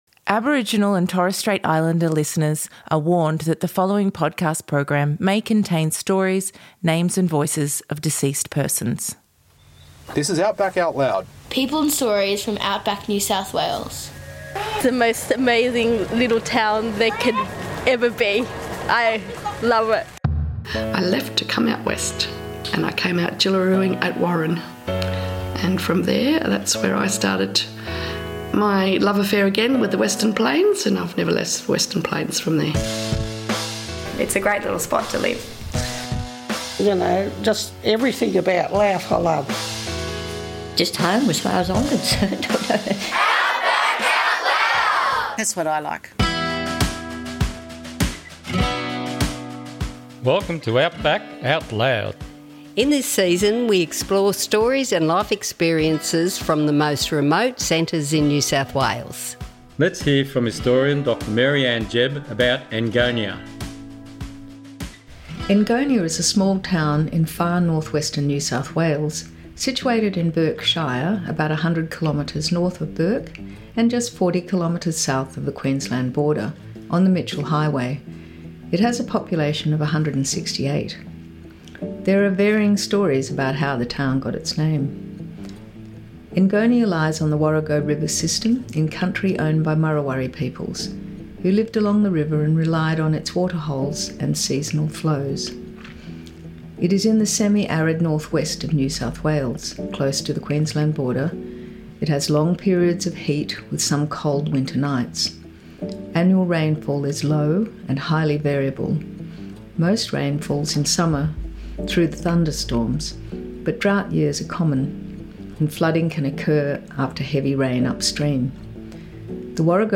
Recorded on Muruwari and Barkindji Country In this episode, we travel north of Bourke to Enngonia, a small outback town where the red dirt roads lead to big stories, deep cultural roots, and a community bound by resilience and pride.